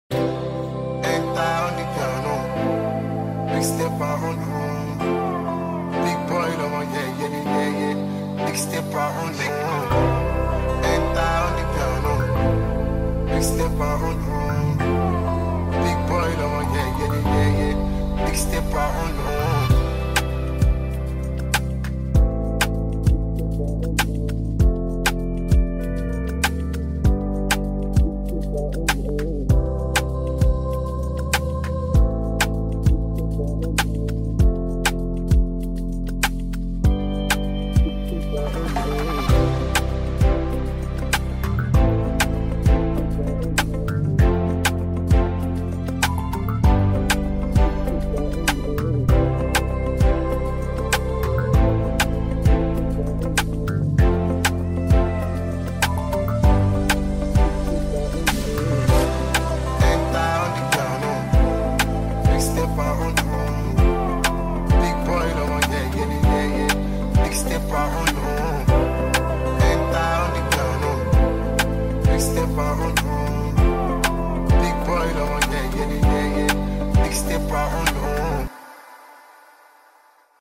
and Afro-fusion sound